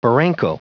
Prononciation du mot barranco en anglais (fichier audio)
Prononciation du mot : barranco